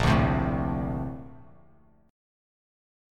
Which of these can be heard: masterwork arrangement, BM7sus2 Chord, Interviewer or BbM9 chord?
BbM9 chord